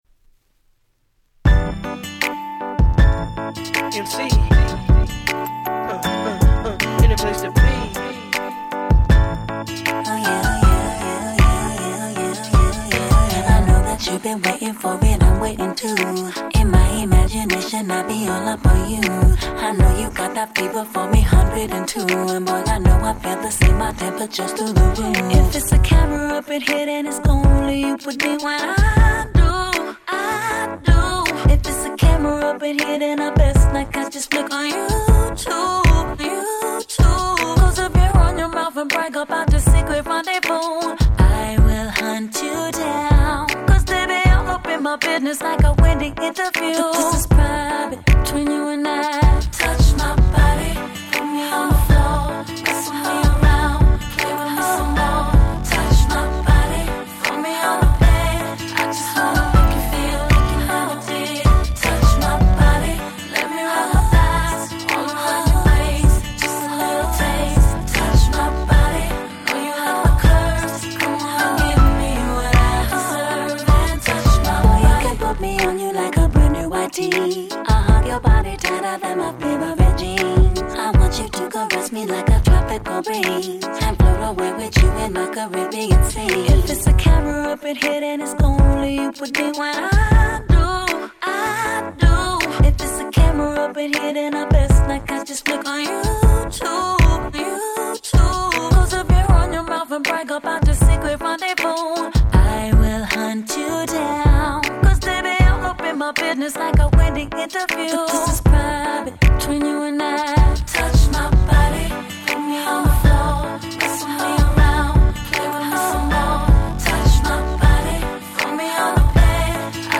08' Super Hit R&B !!